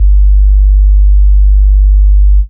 Sub Juno G1.wav